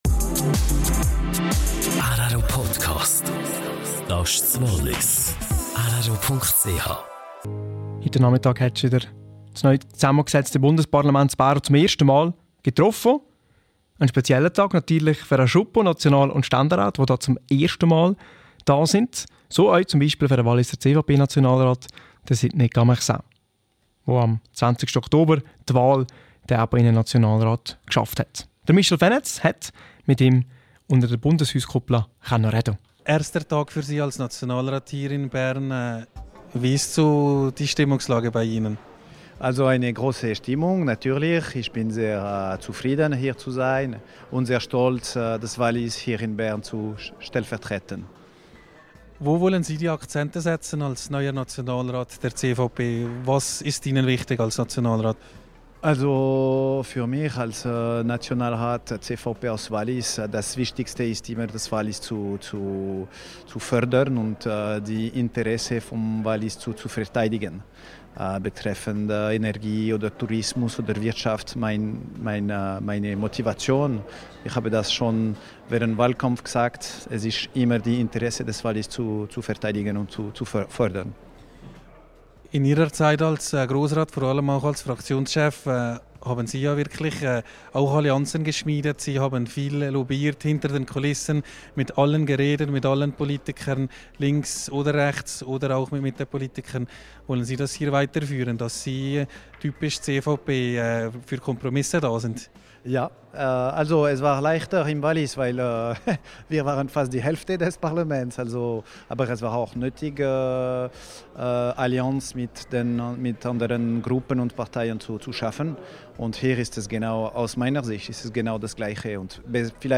Neue Legislaturperiode in Bundesbern - Interview mit den Walliser National- und Ständeräten.